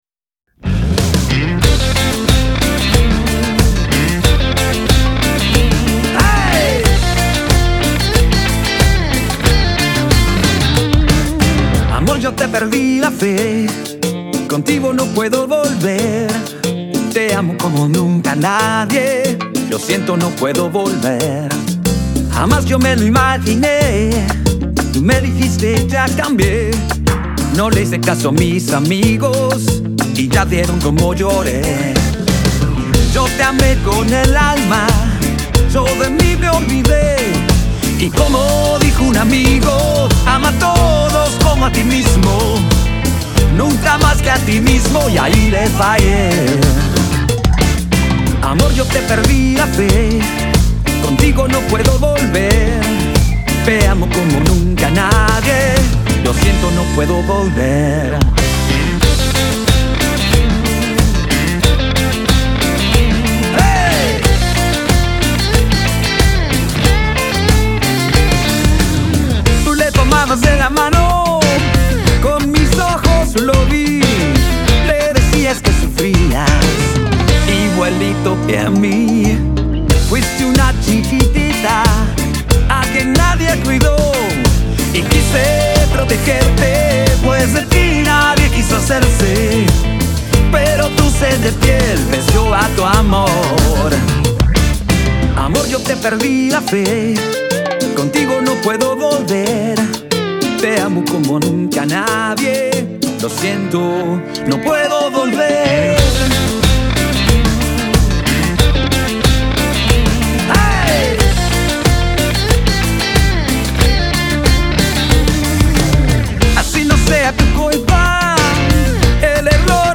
produce y masteriza en Miami
cumbia-pop